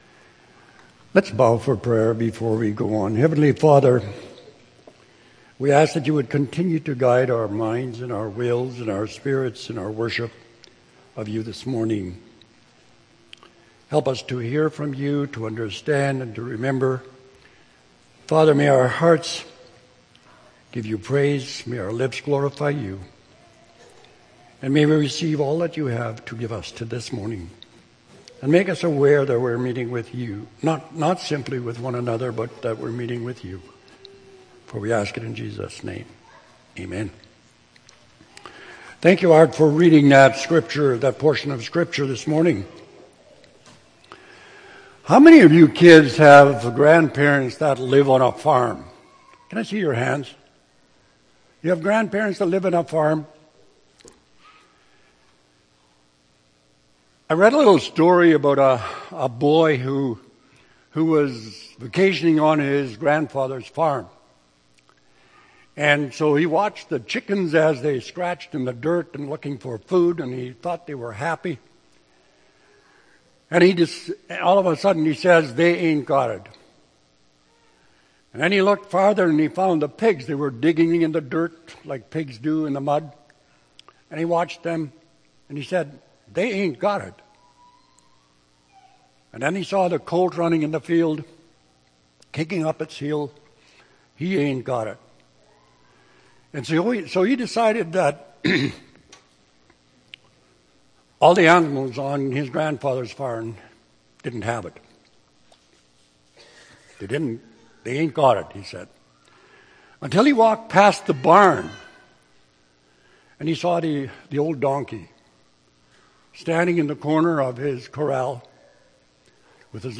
July 28, 2013 – Sermon